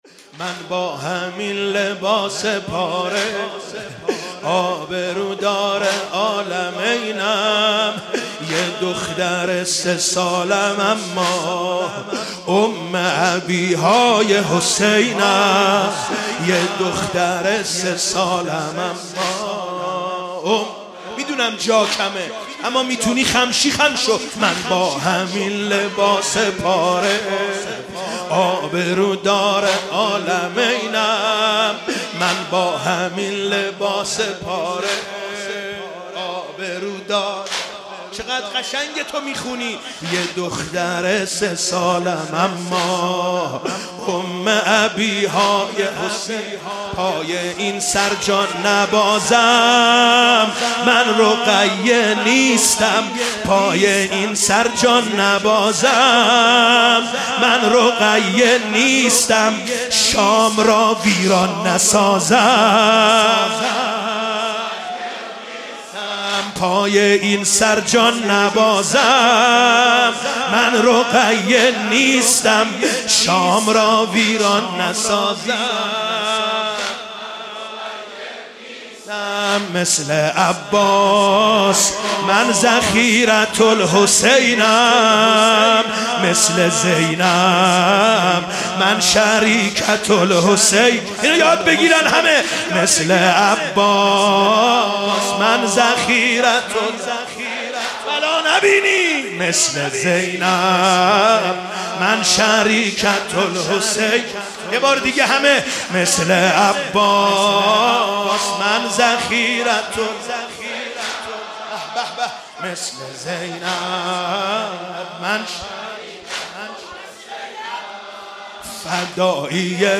مداحی شب 3 سوم محرم 1402
mn_b_hmyn_lbs_prh_abrw_dr_lmynm_-_zmynh.mp3